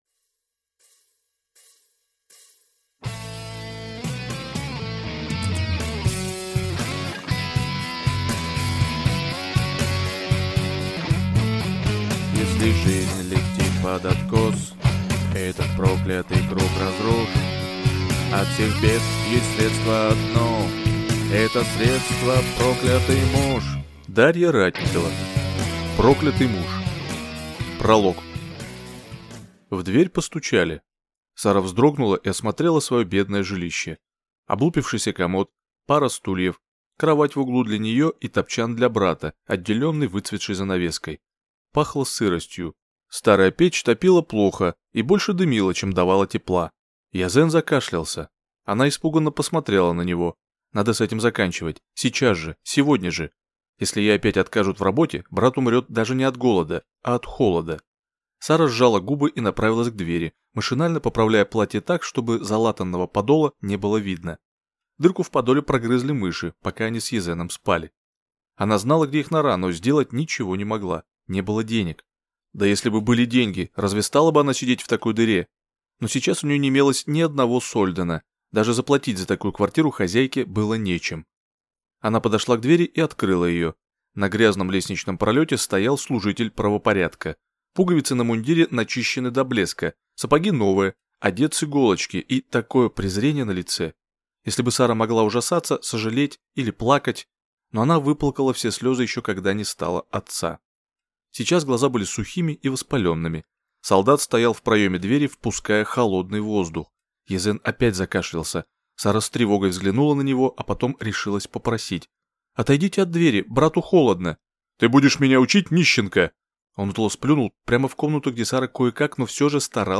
Аудиокнига Проклятый муж | Библиотека аудиокниг